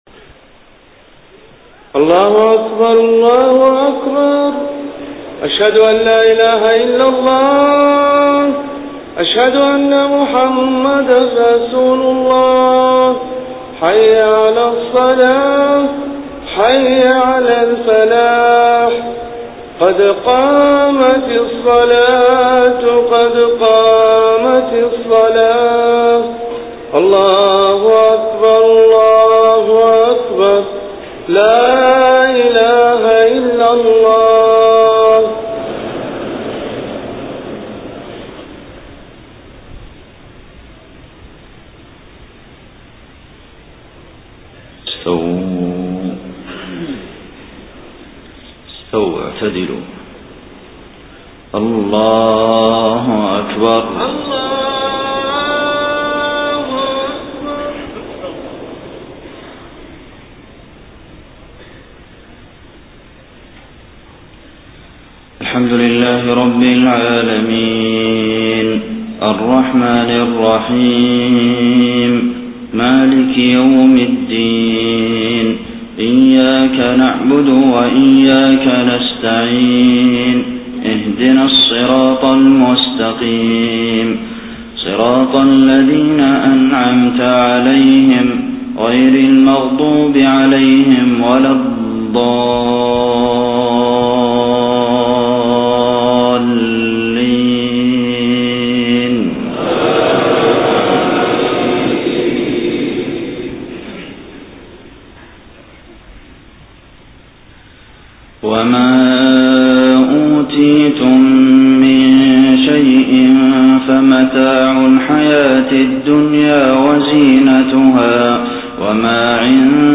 صلاة المغرب 29 صفر 1431هـ من سورة القصص 60-70 > 1431 🕌 > الفروض - تلاوات الحرمين